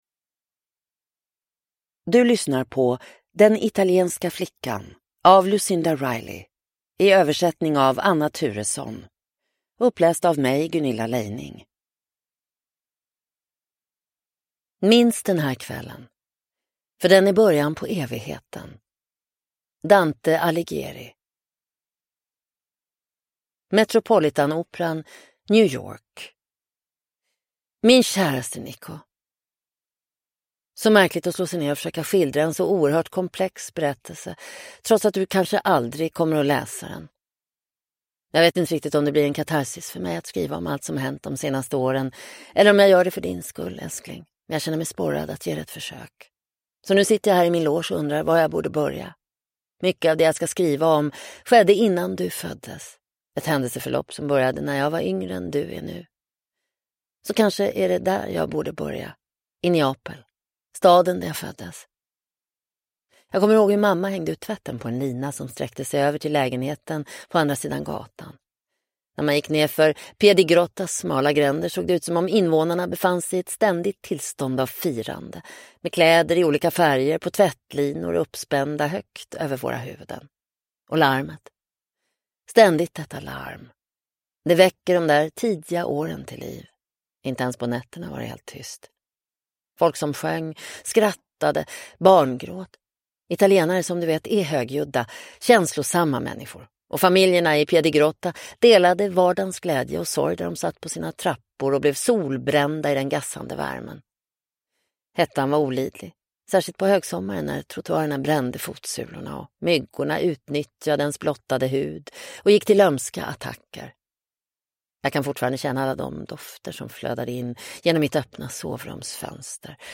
Nedladdningsbar Ljudbok
Ljudbok